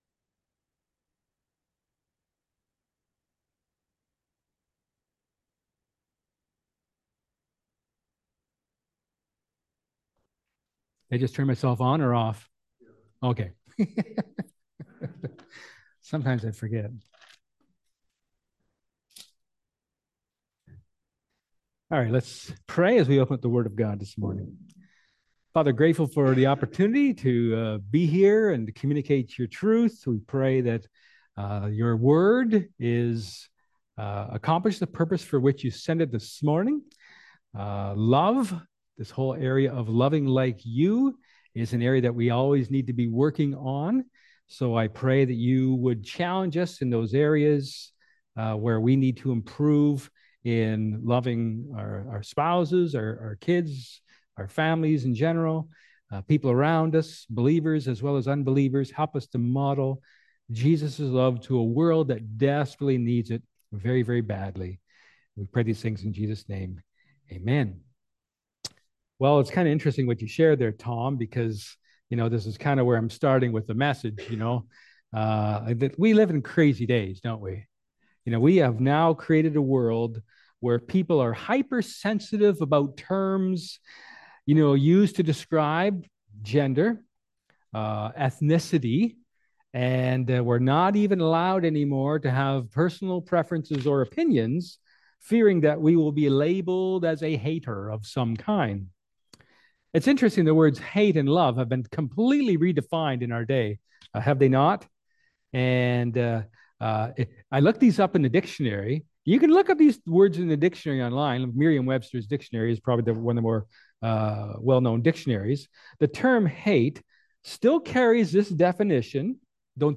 2 Coritnhians 10:1-6 Service Type: Sermon